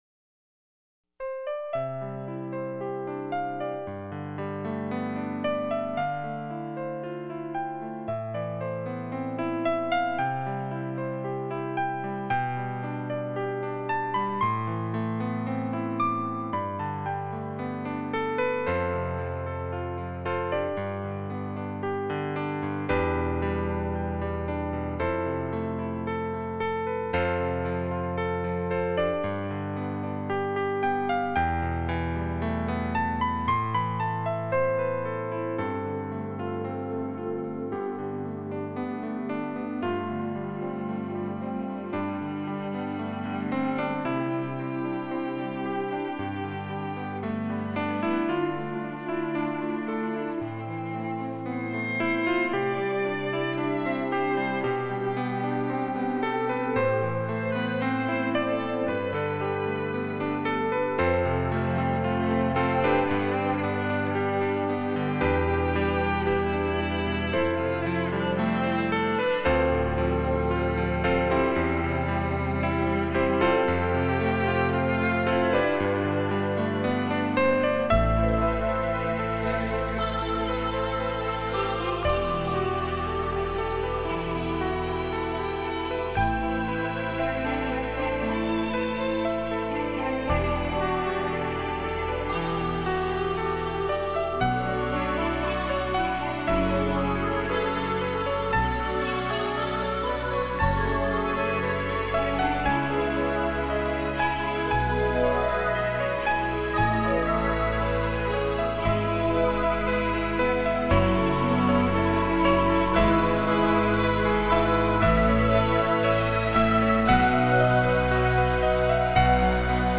样本格式    : 44.100 Hz;16 Bit;立体声
其音乐旋律优美、清新、恬和，明媚如秋阳照水，温婉如霁月和风。新世纪音乐的简约、抒情，加上女性特有的细腻、平和、温柔。